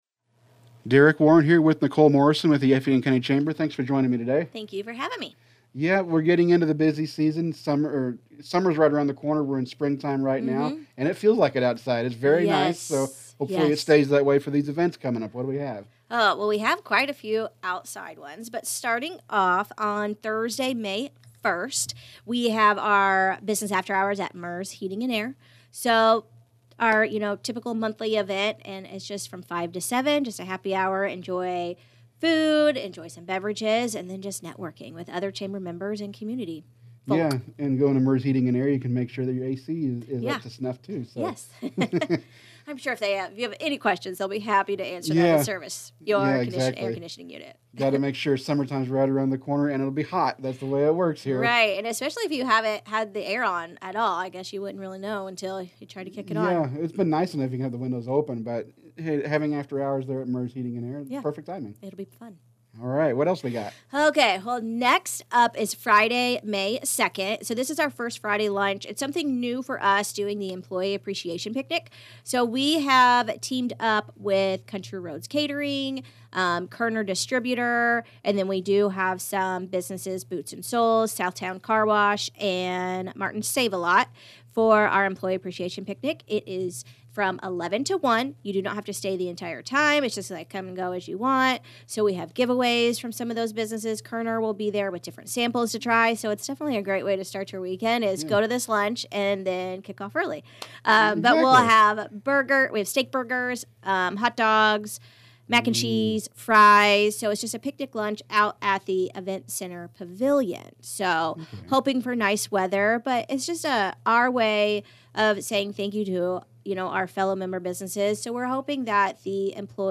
May Chamber Events Detailed In Interview